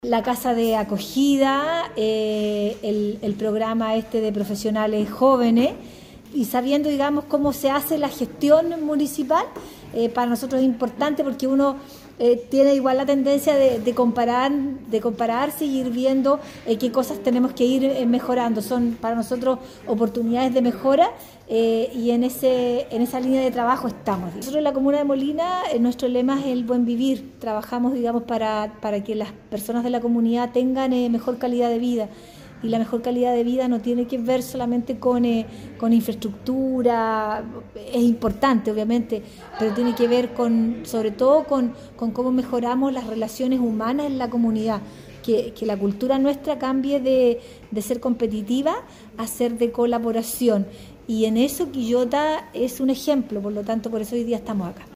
Priscilla-Castillo-Gerli-alcaldesa-de-la-comuna-de-Molina-1.mp3